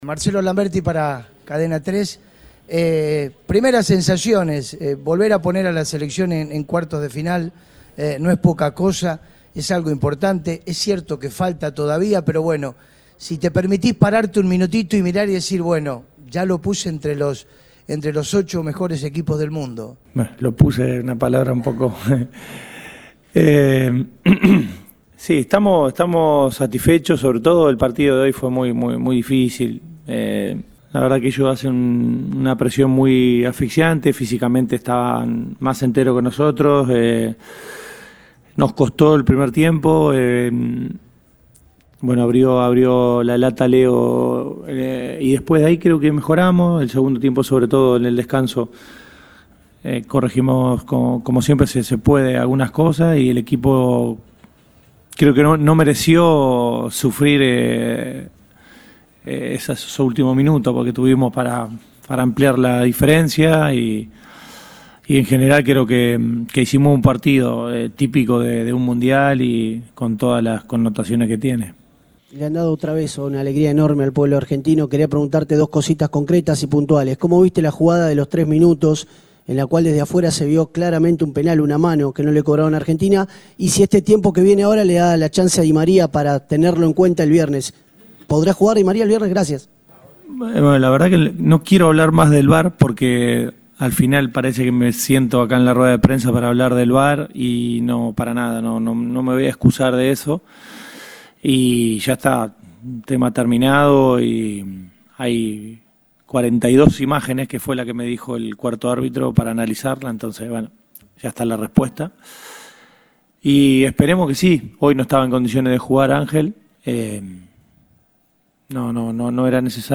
El entrenador de la Selección argentina habló en conferencia de prensa tras el triunfo sobre Australia y advirtió que Países Bajos será "un rival difícil, como todos".